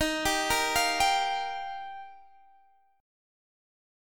D#add9 chord